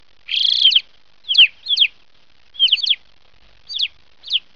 Alpine chough
Alpine-Chough.mp3